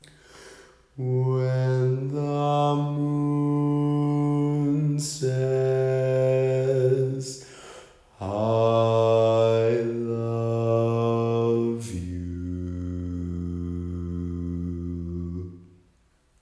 Key written in: F Major
Type: Barbershop
Each recording below is single part only.